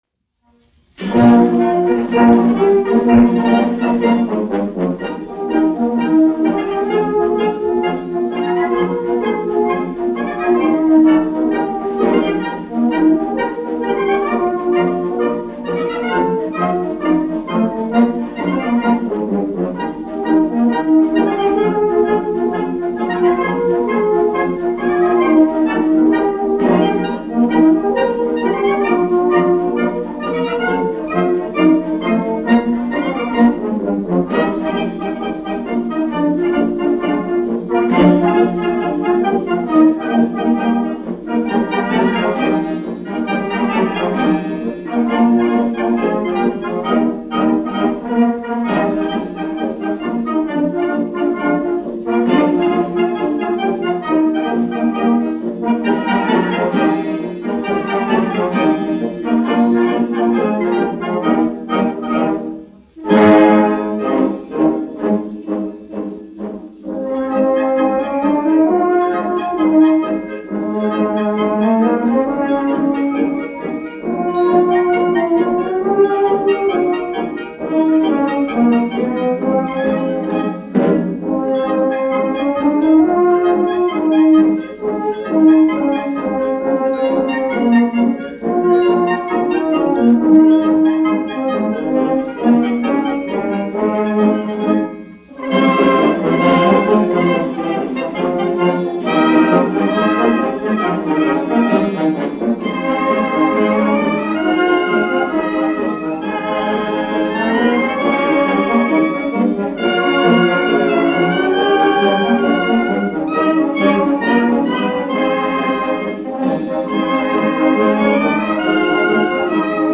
1 skpl. : analogs, 78 apgr/min, mono ; 25 cm
Marši
Pūtēju orķestra mūzika
Latvijas vēsturiskie šellaka skaņuplašu ieraksti (Kolekcija)